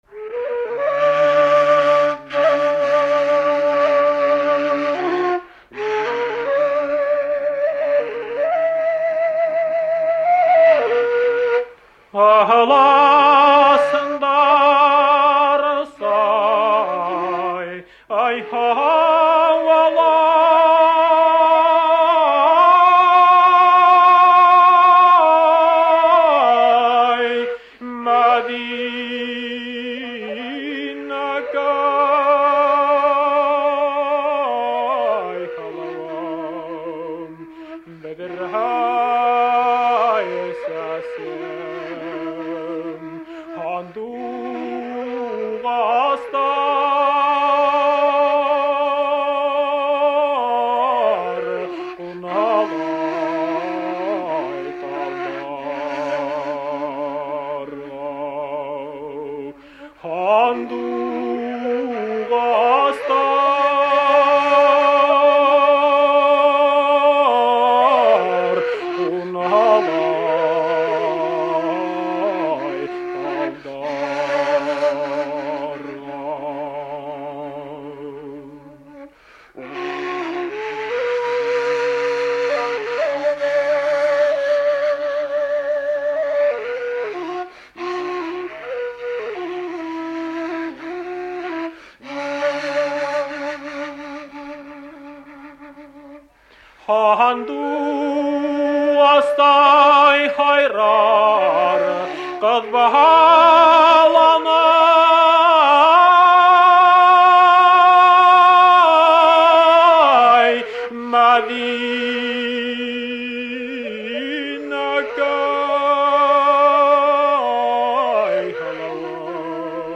“МАДИНАКАЙ” (“Мәҙинәкәй”), баш. нар. песня, узун-кюй.
Песня лирич. характера, имеет переменную метроритмическую структуру. Напев богато орнаментирован внутрислоговыми распевами, достигающими объёма квинты и сексты от опеваемого звука. Поступенное восходящее и нисходящее движение мелодии придаёт песне особую выразительность.